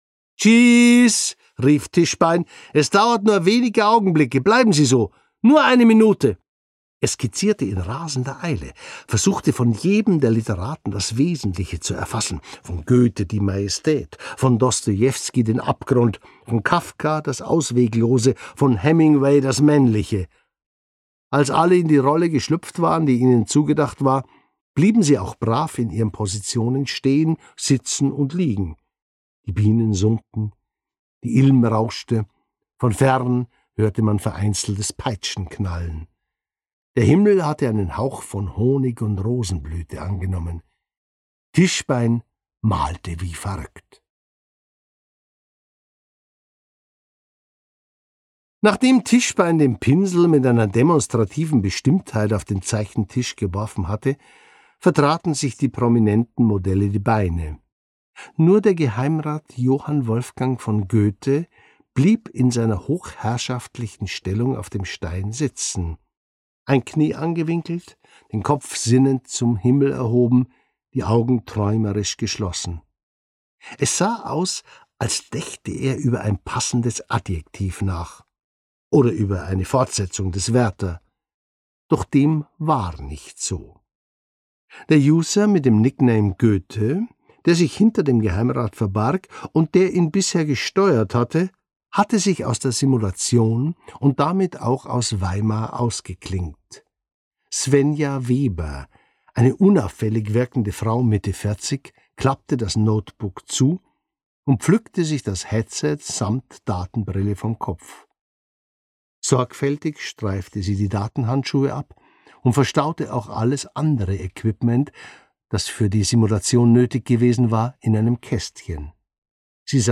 Gekürzt Autorisierte, d.h. von Autor:innen und / oder Verlagen freigegebene, bearbeitete Fassung.
Kommissar Jennerwein und der tintendunkle Verdacht Gelesen von: Jörg Maurer